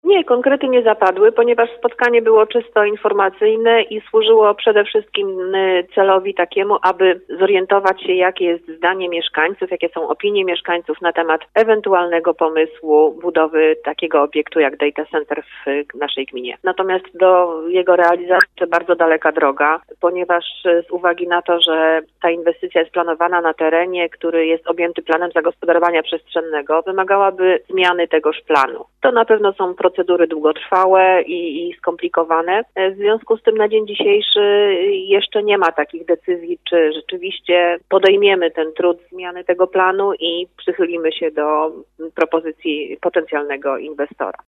Twoje Radio zapytało Julitę Pilecką, wójt gminy Kobylanka, czy podczas spotkania zapadły jakieś konkretne decyzje?